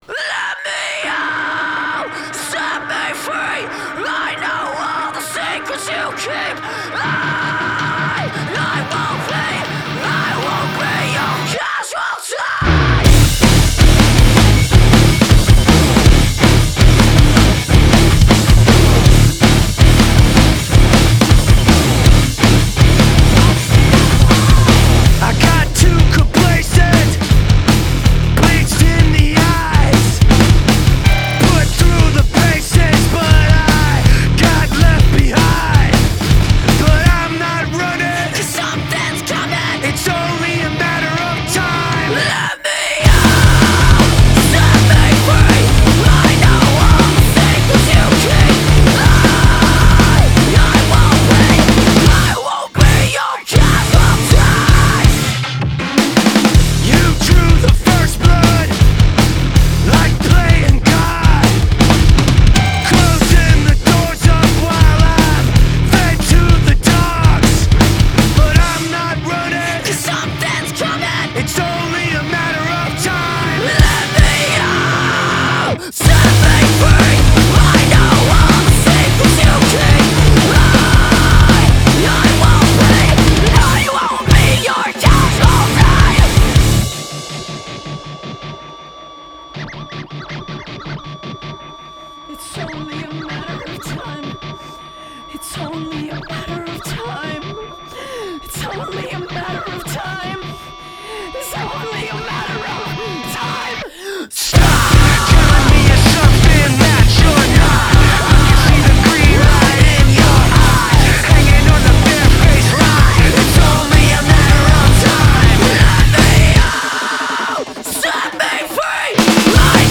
alternative metal nu metal